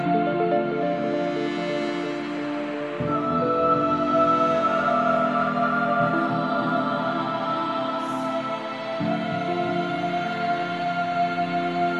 描述：电风琴与一些黑暗的合唱团。
Tag: 121 bpm Trap Loops Organ Loops 2.67 MB wav Key : D